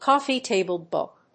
アクセントcóffee‐tàble bóok